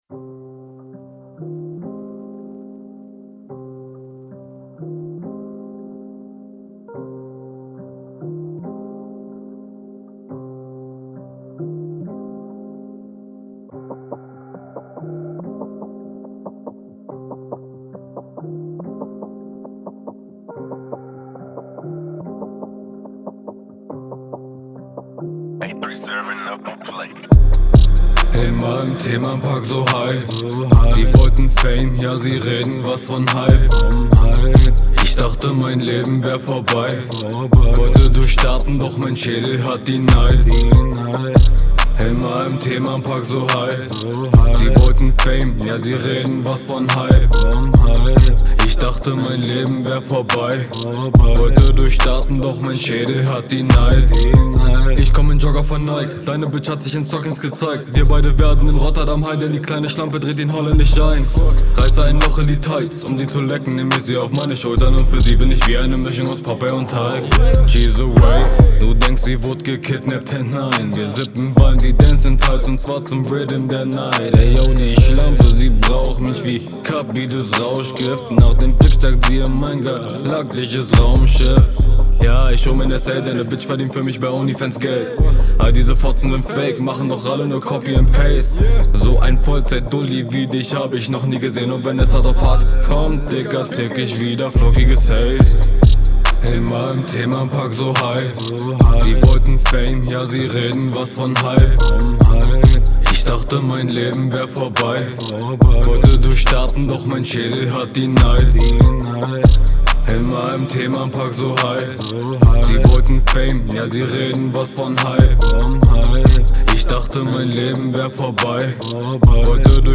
Soundtechnisch besser als deine HR1, aber battletechnisch genau so unterlegen.